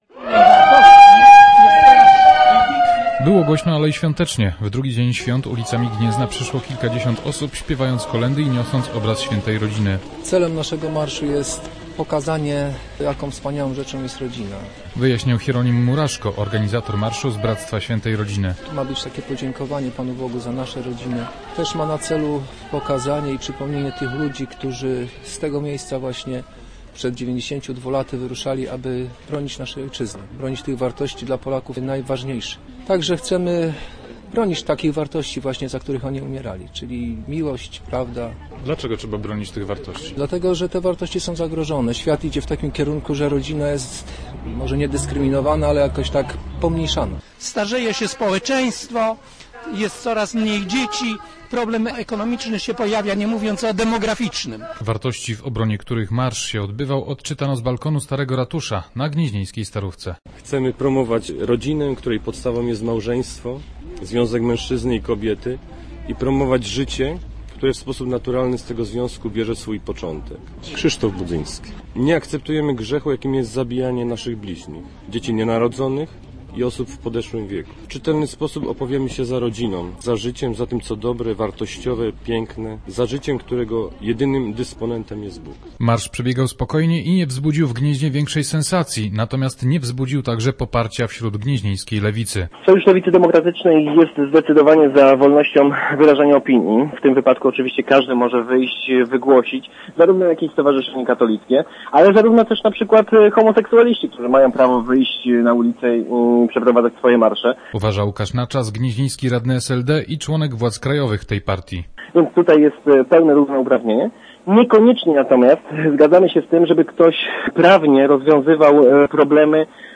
Marszem w obronie życia uczczono w Gnieźnie obchodzony w kościele katolickim dzień św. Rodziny. Kilkadziesiąt osób śpiewając kolędy przeszło przez starówkę do katedry.